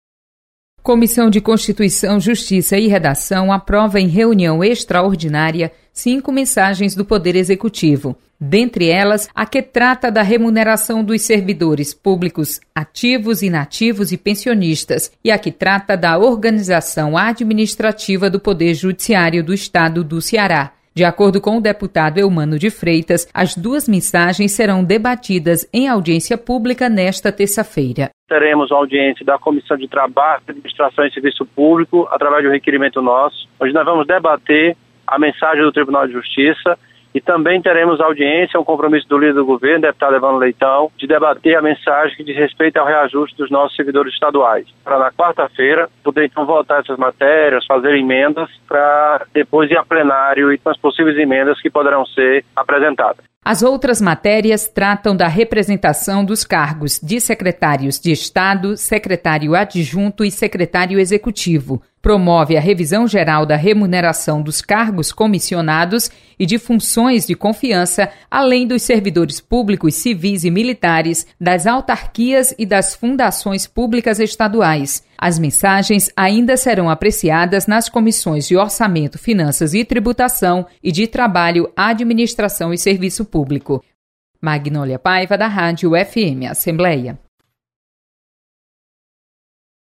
CCJ aprova mensagem de remuneração dos servidores públicos do Estado. Repórter